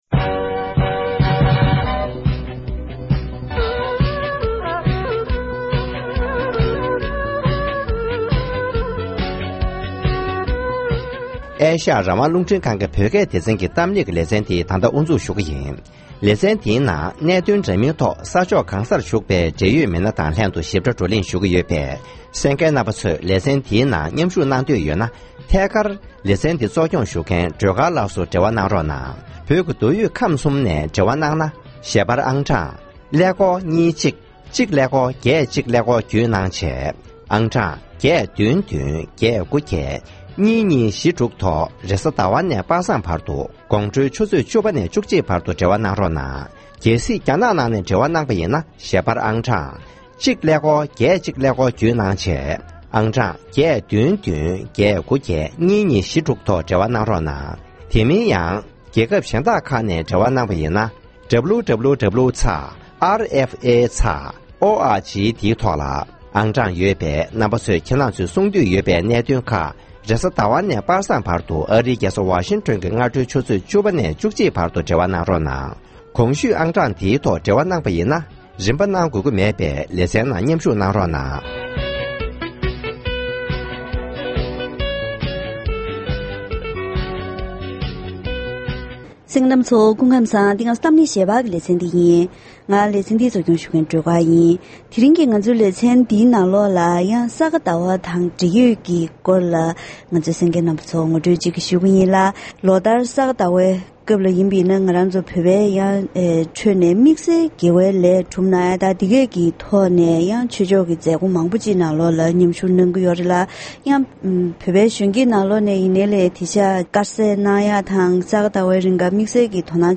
བཀའ་མོལ་ཞུས་པ་ཞིག་གསན་རོགས་གནང་།